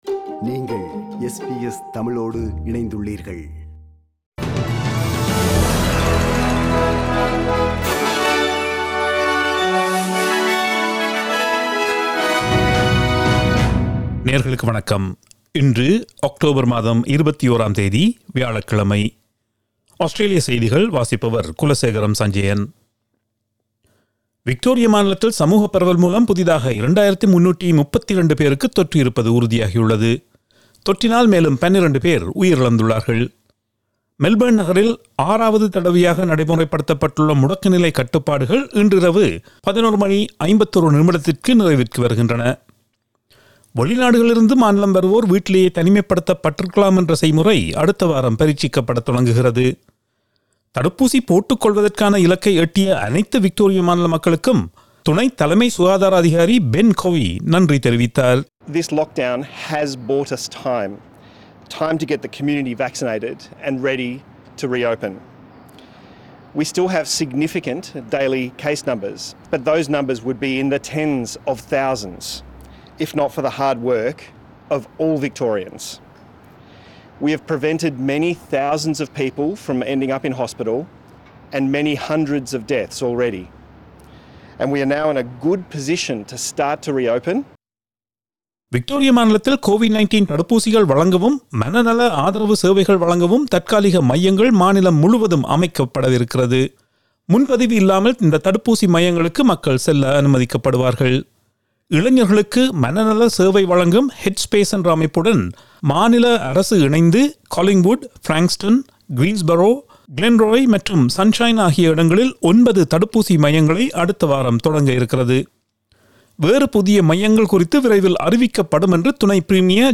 Australian news bulletin for Thursday 21 October 2021.